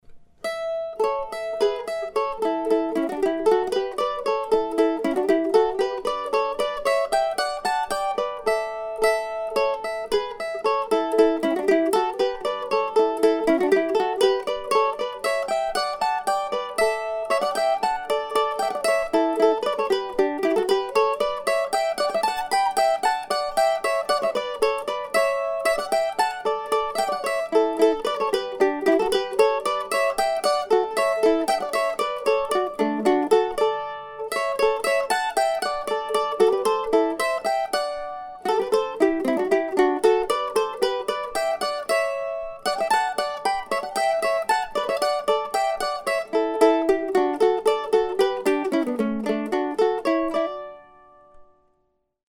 This time a collection of duos for two mandolins.